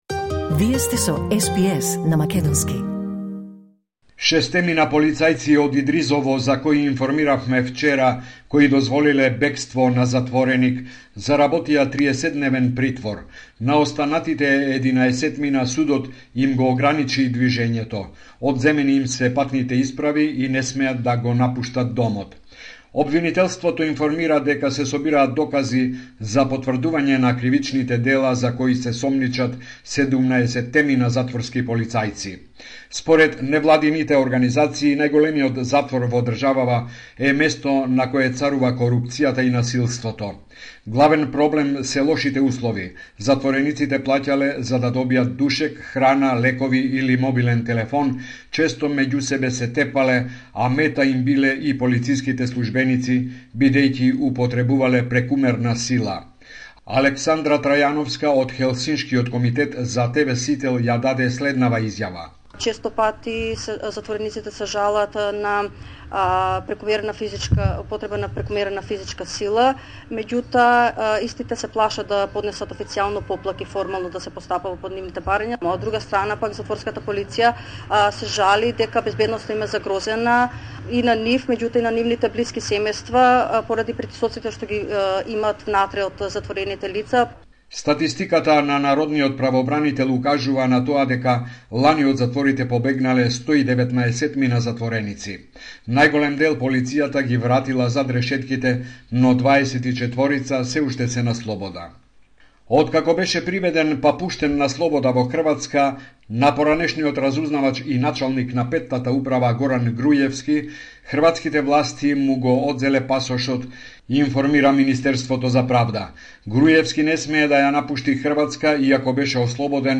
Homeland Report in Macedonian 17 April 2024